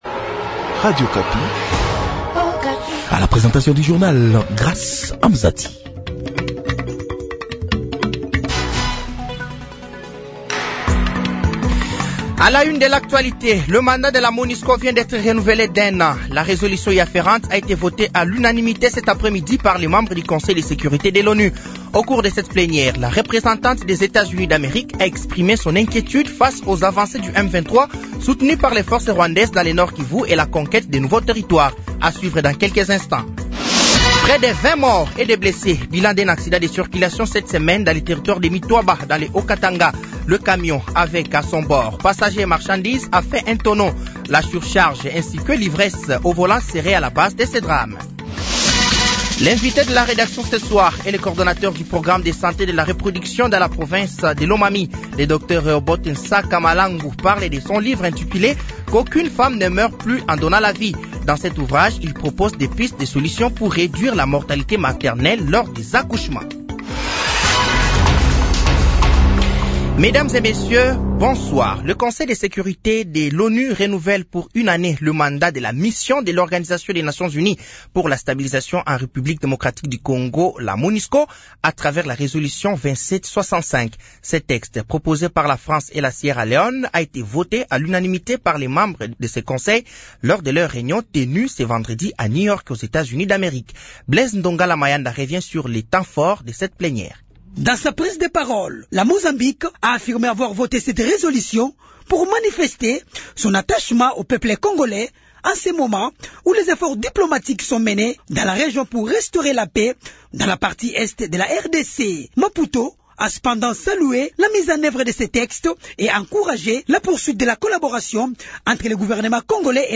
Journal français de 18h de ce vendredi 20 décembre 2024